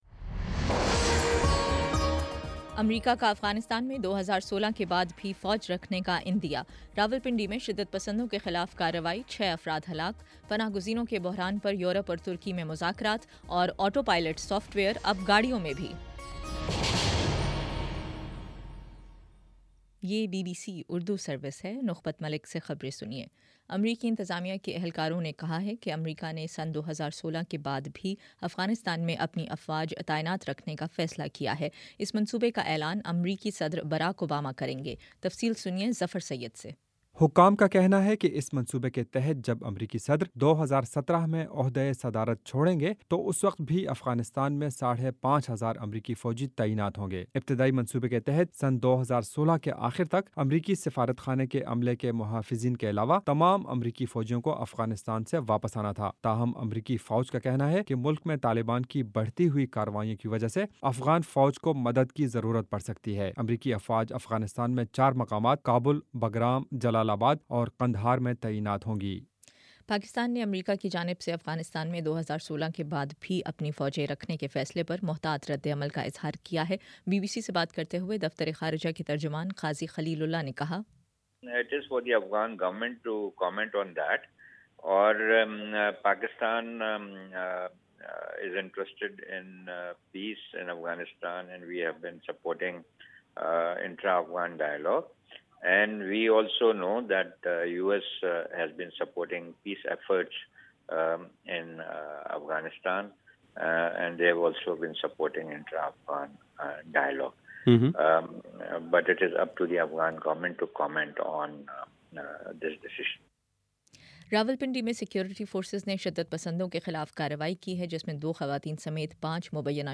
اکتوبر 15 : شام چھ بجے کا نیوز بُلیٹن